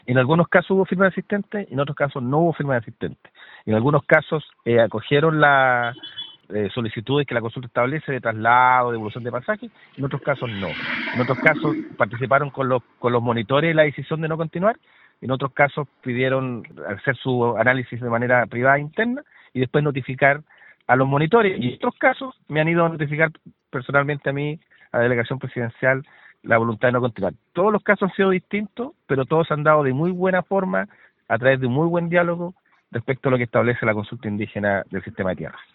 El delegado Presidencial de Los Ríos, Jorge Alvial, como vocero del proceso consultivo, señaló que todos los casos han sido distintos, de muy buena forma a su juicio y de acuerdo lo que establece la consulta indígena.